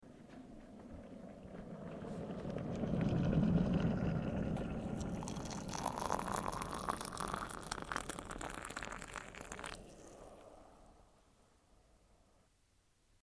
Звуки чашки
Шум от заливания кипятка в чай или кофе